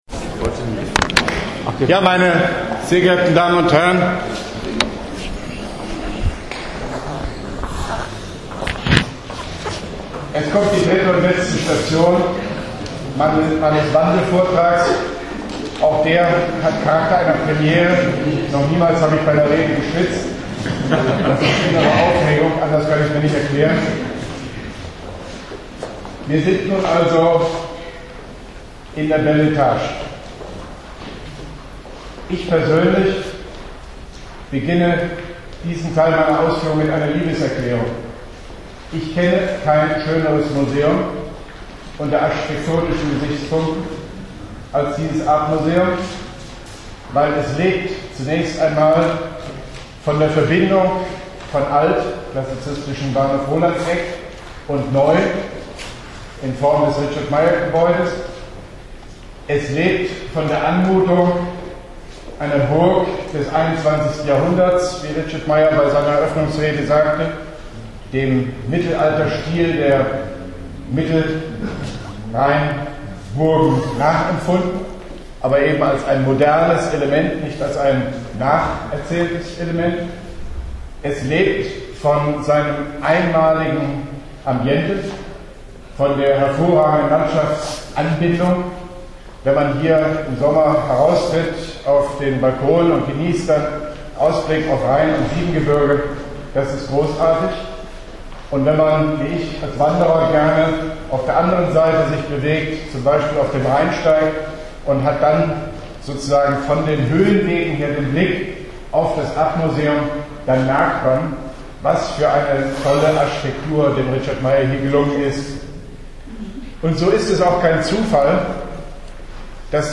Arp Vortrag Dritte Station
Arp-Vortrag-Dritte-Station.mp3